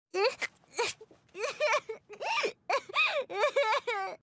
satania-cry.mp3